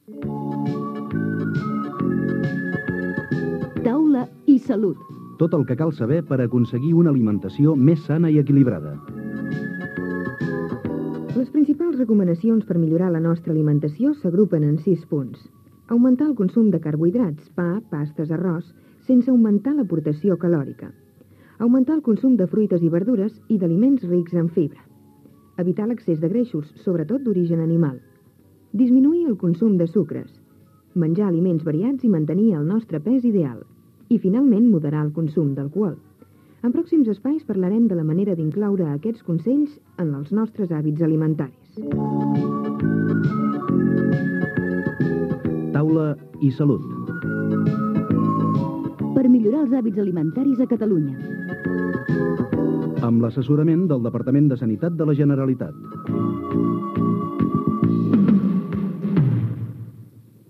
Careta del programa, Sis consells per millorar la nostra salut amb els hàbits alimentaris.
Careta del programa. Cal incrementar els carbohidrats sense incrementar el nombre de calories
Divulgació